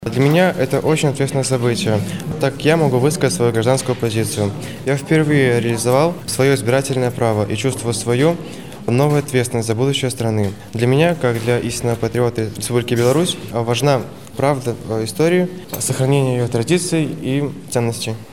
Парень признался, что участие в референдуме для него очень важный момент и большая ответственность за будущее страны.